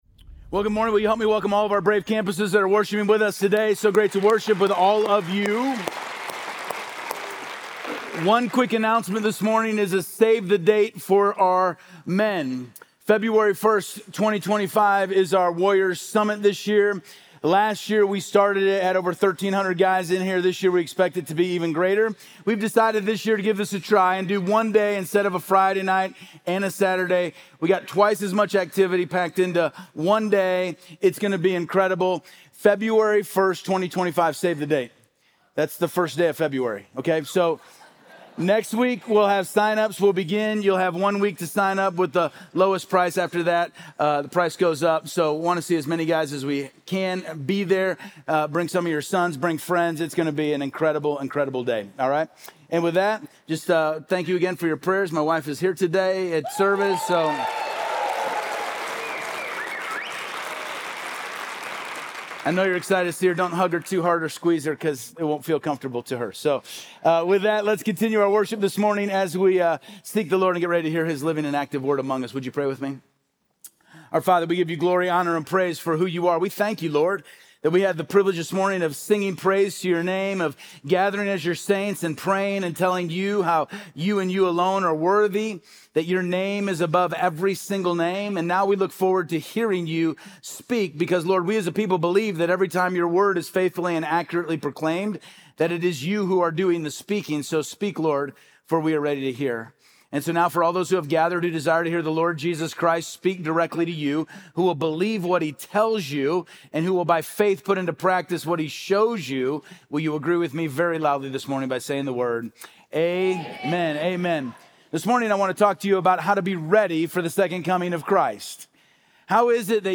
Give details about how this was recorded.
He concluded by reminding the congregation that through grace, believers can live faithfully, peacefully, and authentically as they await Christ's return.